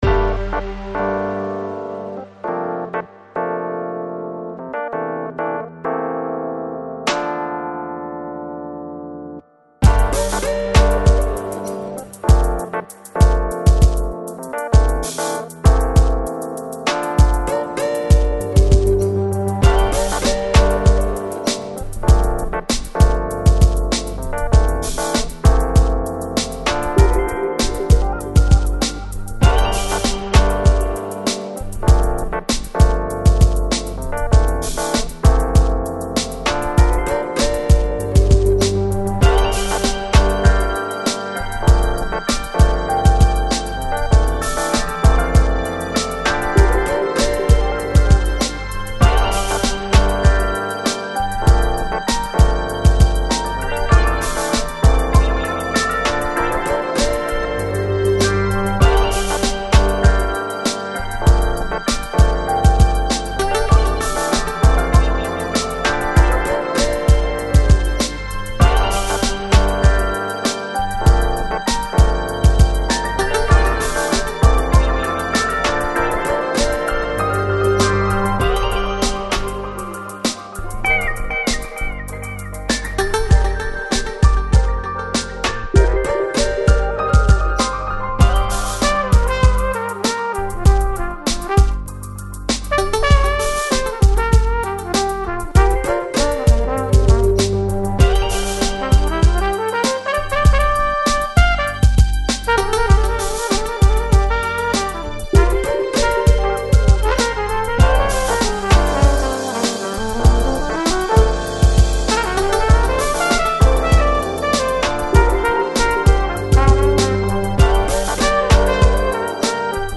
Жанр: Lounge, Chill Out, Jazz